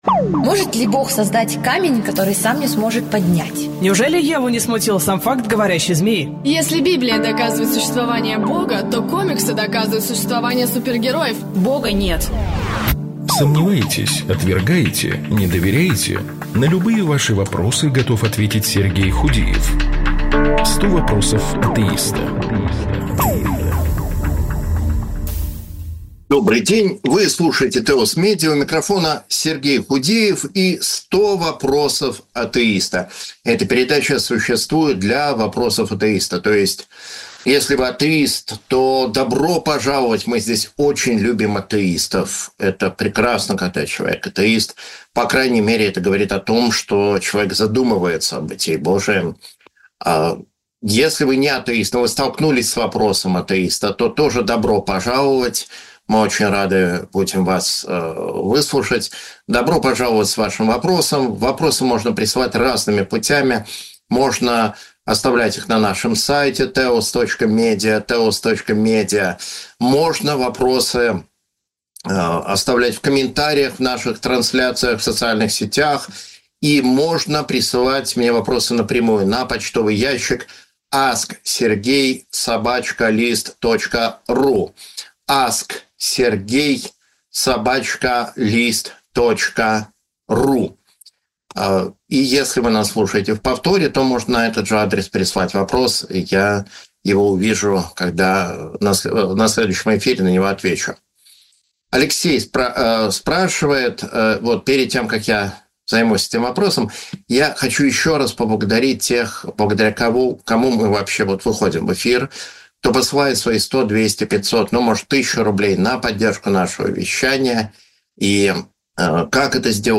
В прямом эфире